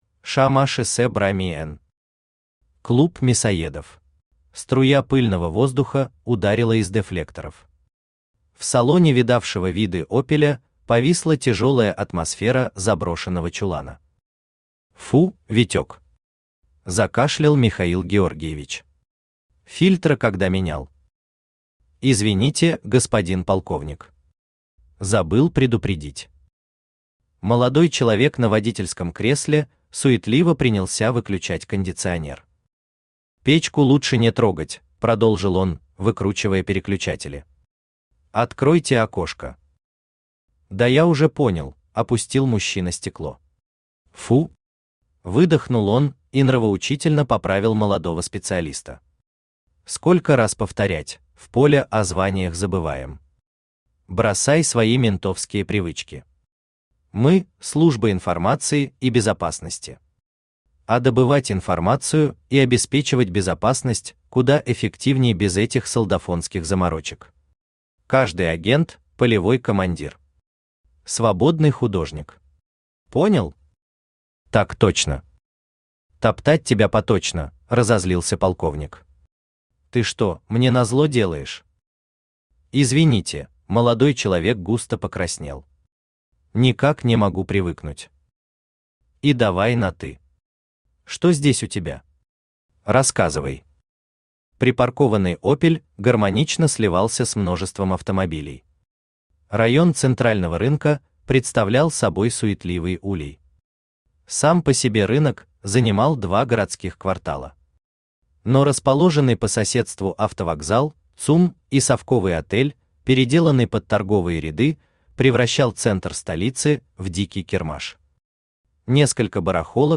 Аудиокнига Клуб мясоедов | Библиотека аудиокниг
Aудиокнига Клуб мясоедов Автор ШаМаШ БраМиН Читает аудиокнигу Авточтец ЛитРес.